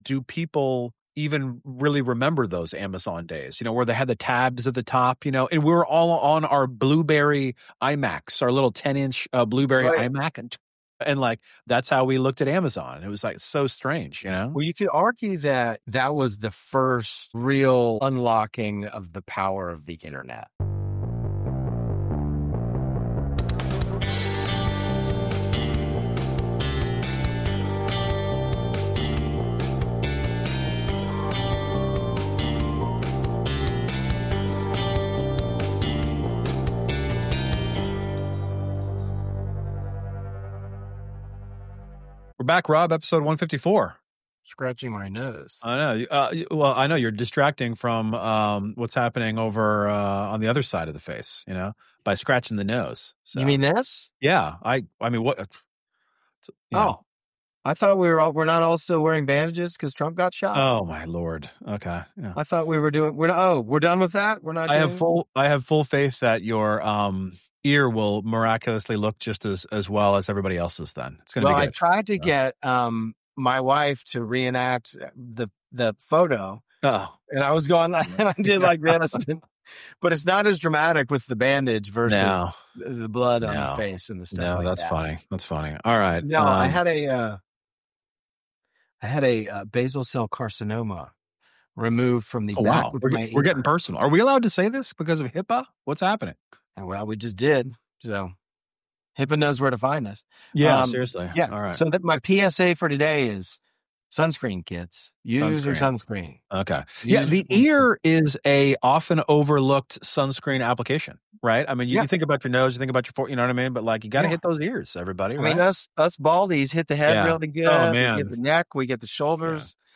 Tune in for a lively conversation that will inspire and entertain, offering a fresh perspective on the ever-changing business landscape.